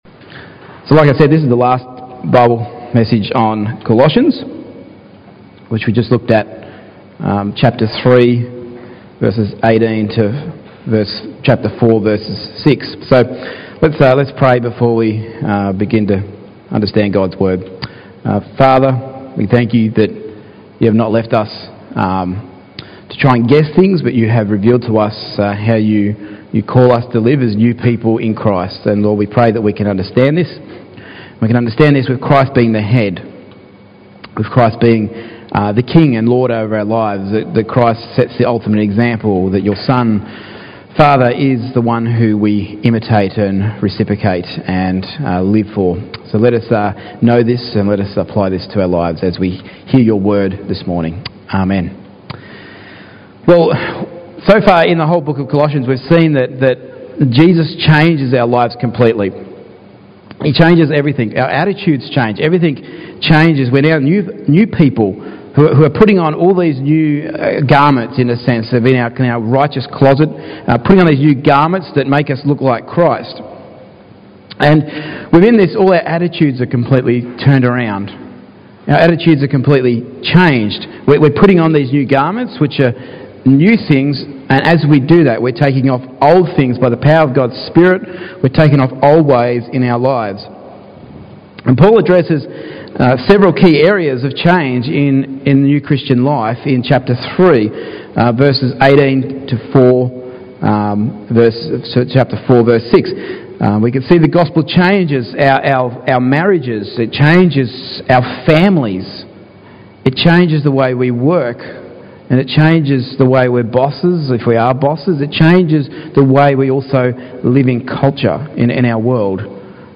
Service Type: Morning Gatherings